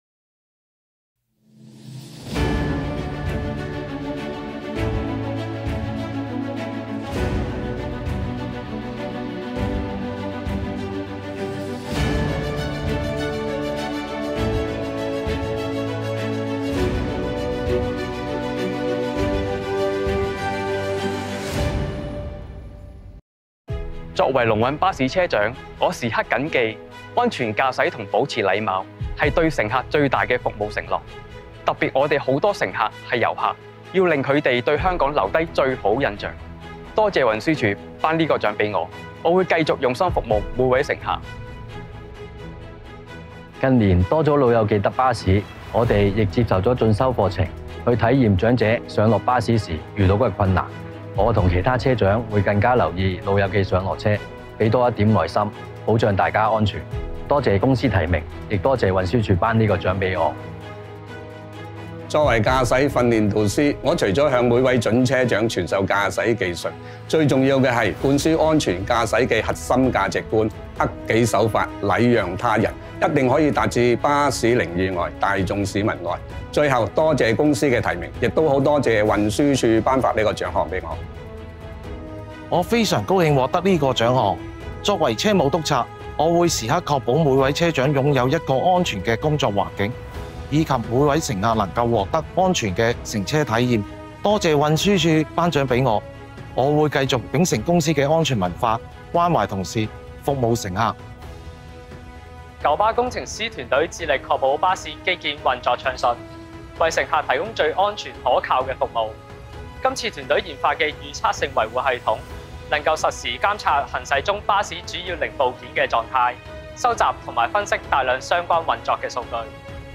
巴士安全卓越奖颁奖典礼2025得奖者感言(只提供广东话版本)
Audio of video_Joy-sharing by award winners of the Bus Safety Excellence Award Ceremony 2025.mp3